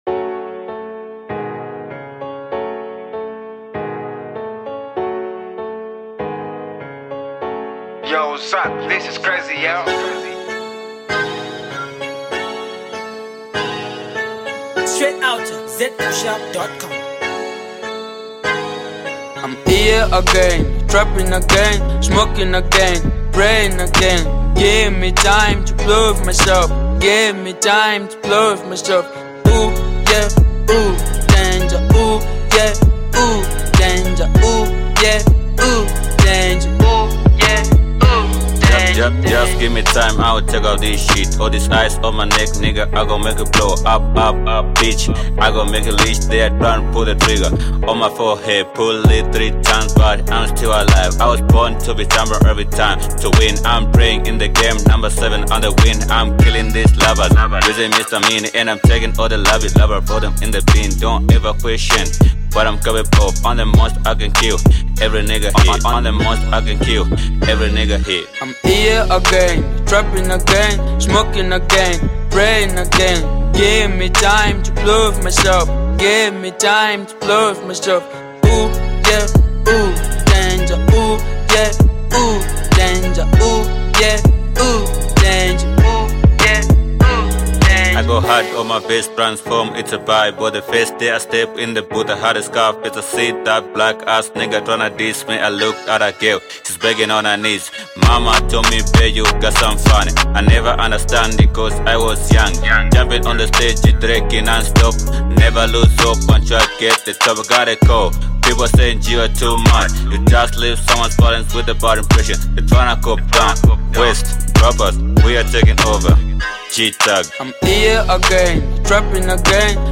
dope trap masterpiece